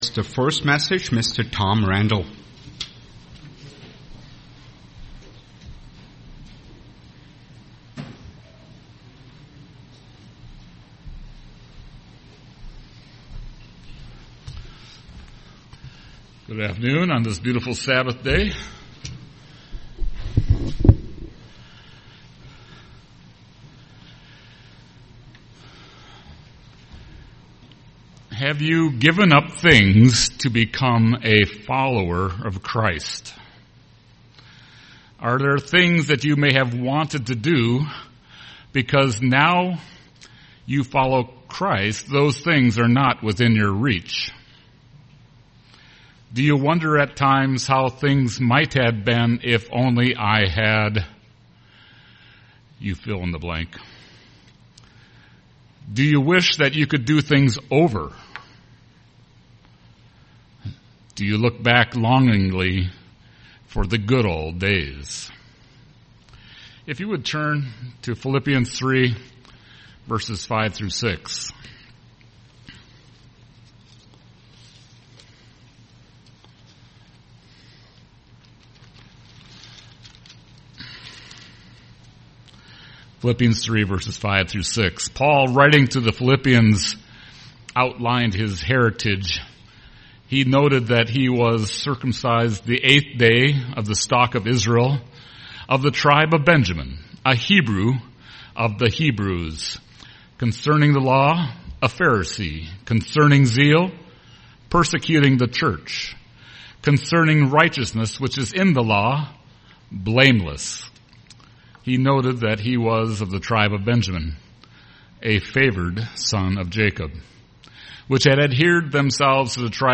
Given in Twin Cities, MN
UCG Sermon sacrifice Studying the bible?